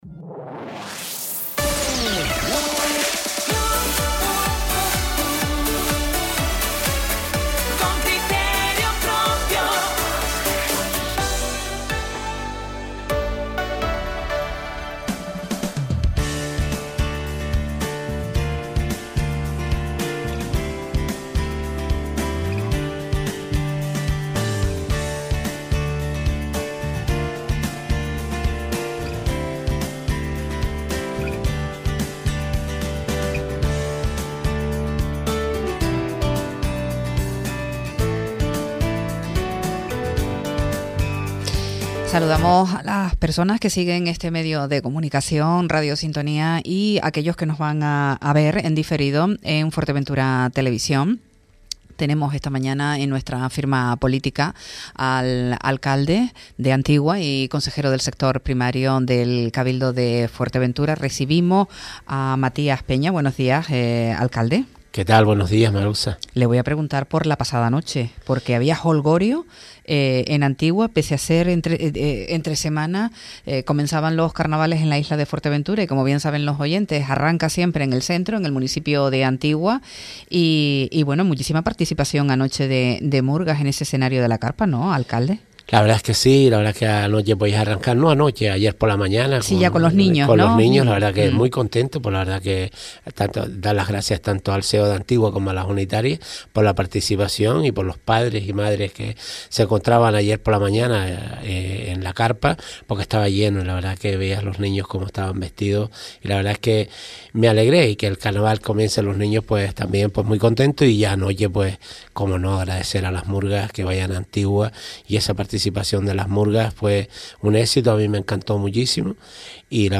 Entrevistas Nos acercamos a la gestión del Gobierno que preside en Antigua el alcalde Matías Peña.